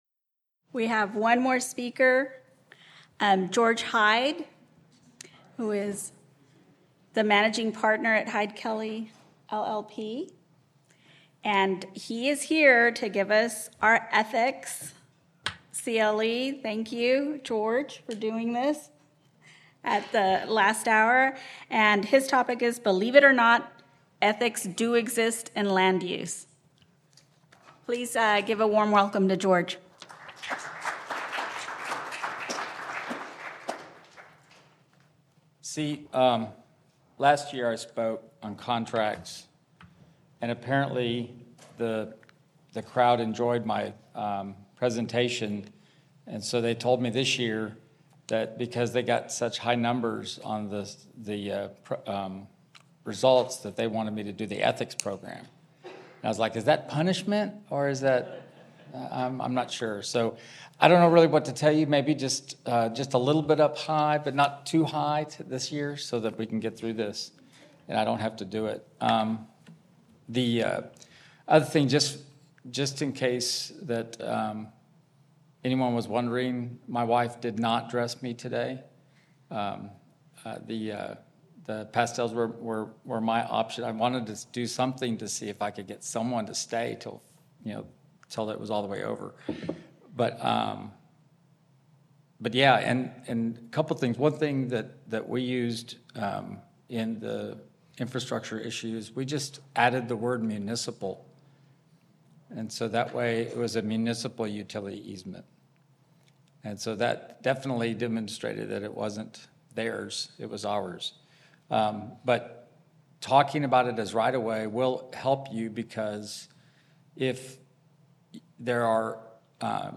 Originally presented: Apr 2024 Land Use Conference